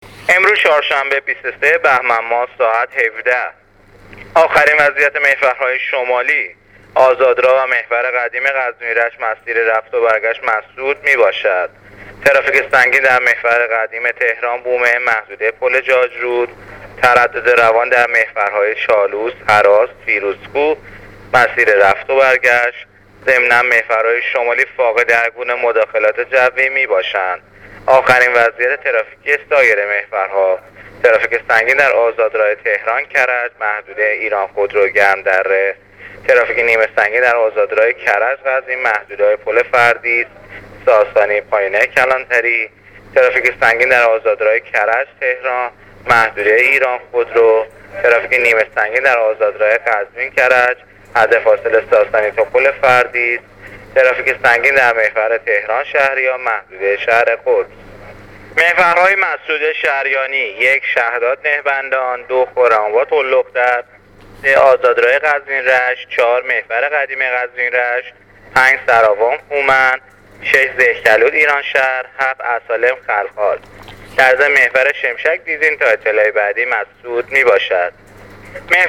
گزارش رادیو اینترنتی از آخرین وضعیت ترافیکی جاده‌ها تا ساعت ۱۷ چهارشنبه ۲۳ بهمن‌ماه ۱۳۹۸